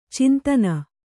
♪ cintana